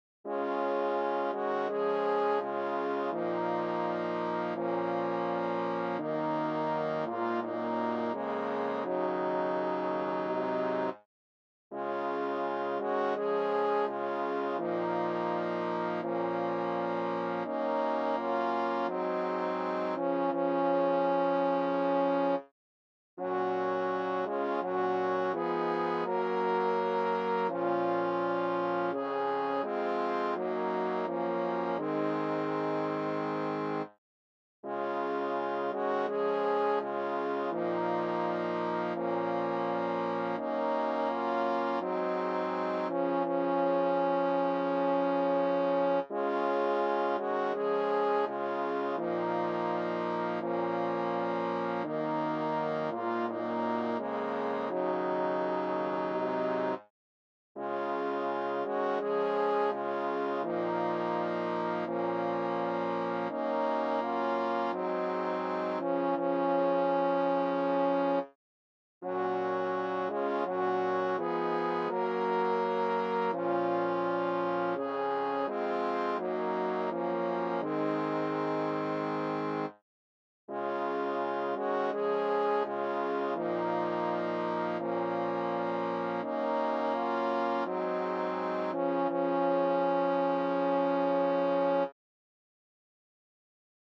3 HORNS, TUBA